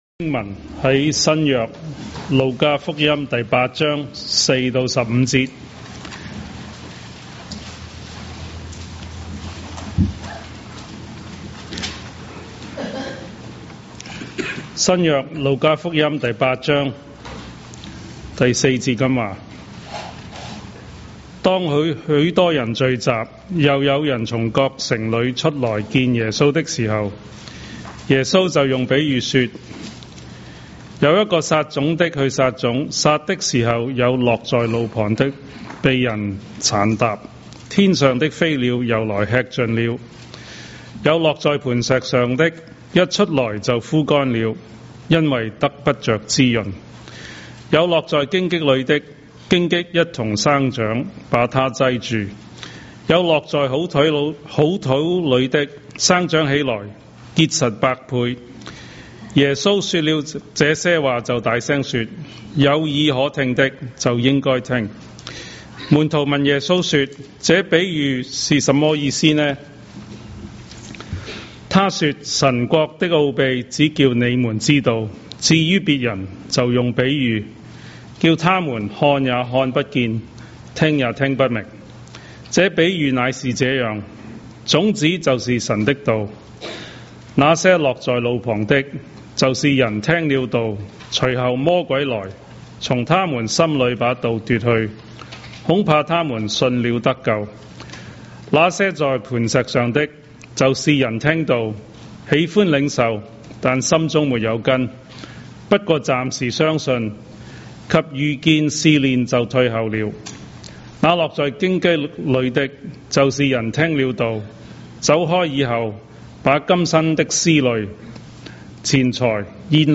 華埠粵語三堂